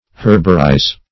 Herbarize \Herb"a*rize\